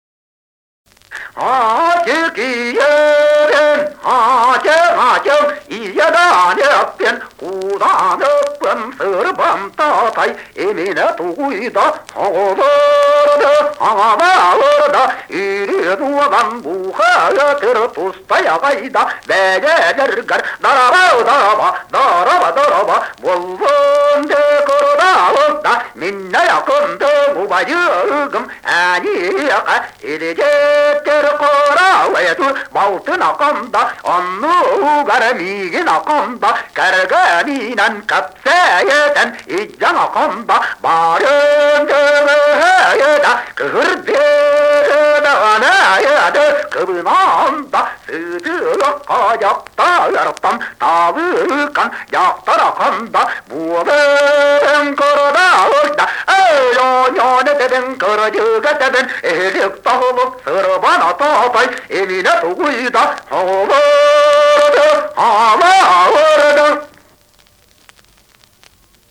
Якутский героический эпос "Кыыс Дэбилийэ"
Песня девы абааhы из олонхо "Yрун Уолан".